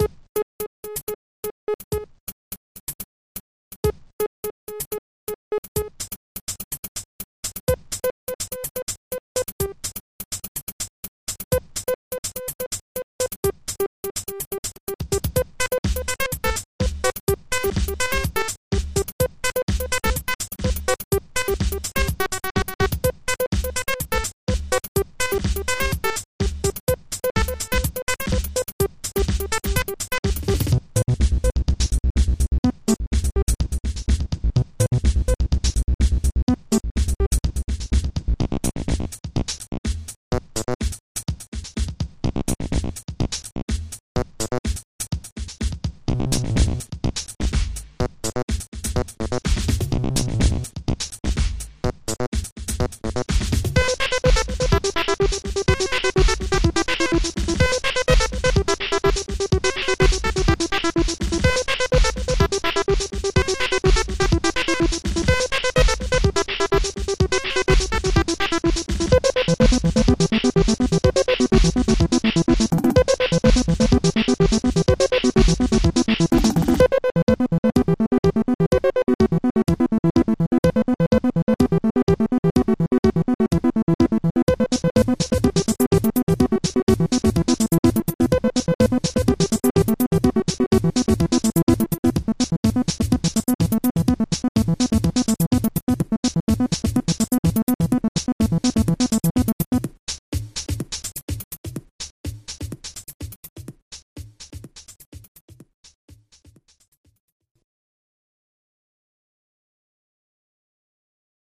Tout ça vient de fast tracker II, sous Dos, à l'époque ou bill gates n'était pas encore maître du monde, à savoir avant 1997.
Alors y'a un peu de tout, on commence par du 4 voies avec
Grey, un morceau qui était fait pour une démo et qui n'a jamais quitté la douce tiedeur de mon disque dur. 4 voies, 4 samples seulement, du 96kb/s pour le mp3 ça mérite pas mieux.